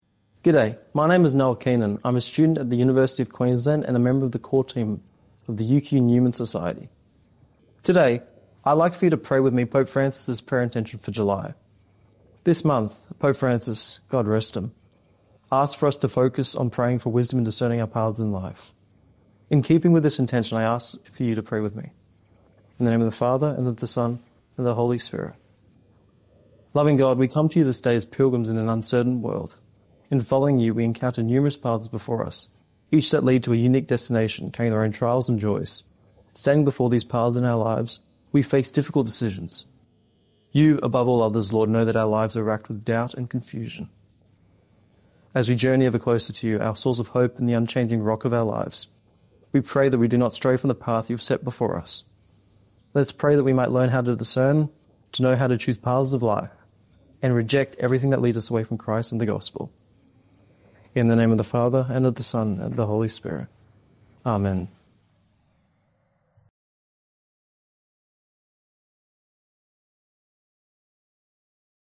Prayer and scriptural reflections, homilies and pastoral messages from Archbishop Mark Coleridge, catechesis and more.